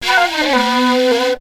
FLUT 03.AI.wav